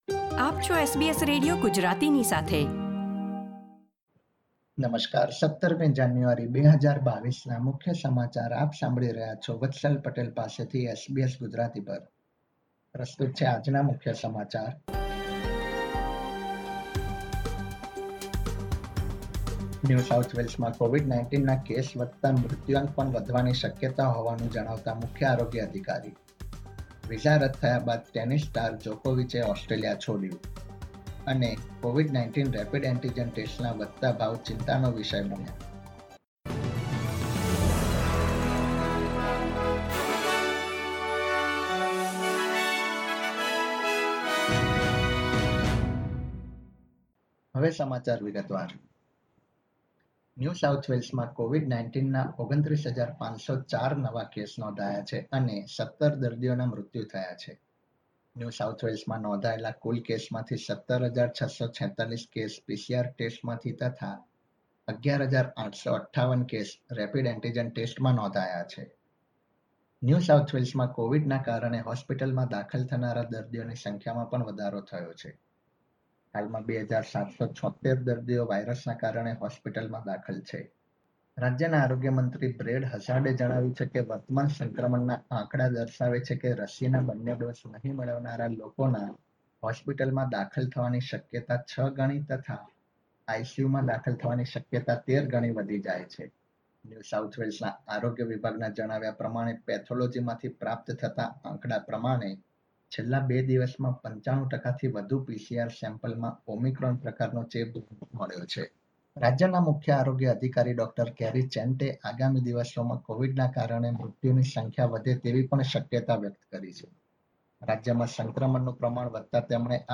SBS Gujarati News Bulletin 17 January 2022